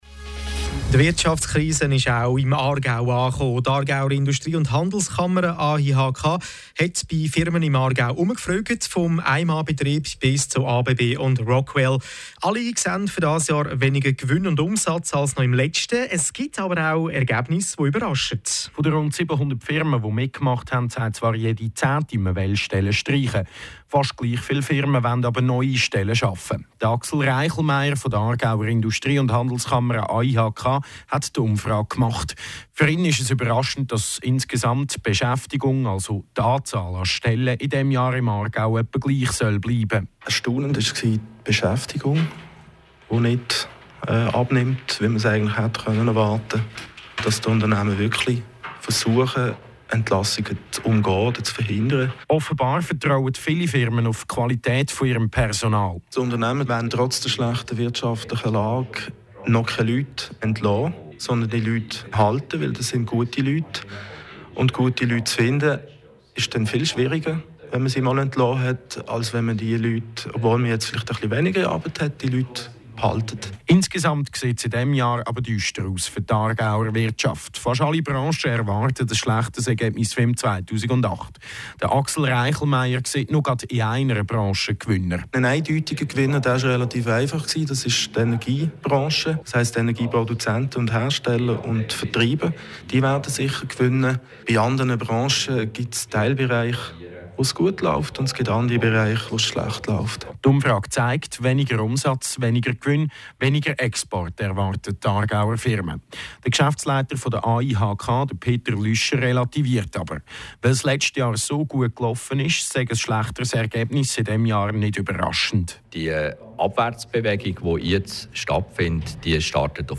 Interview zur AIHK-Wirtschaftsumfrage 2009